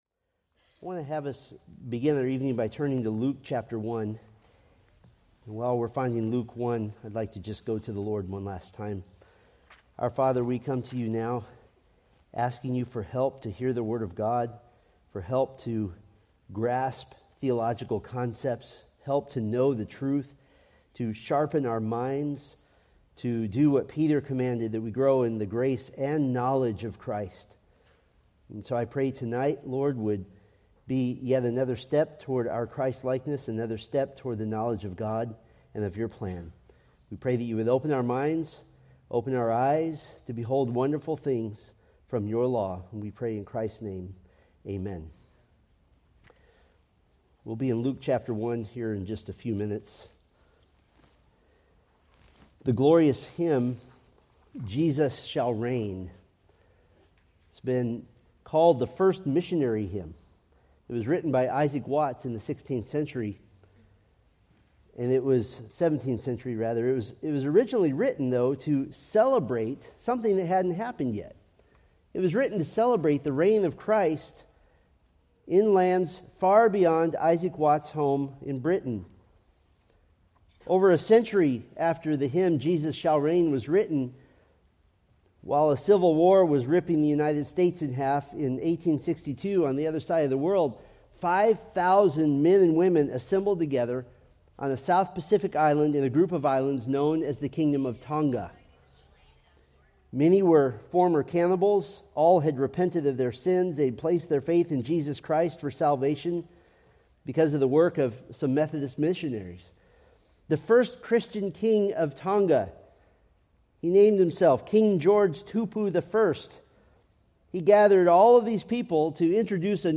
Preached April 13, 2025 from Selected Scriptures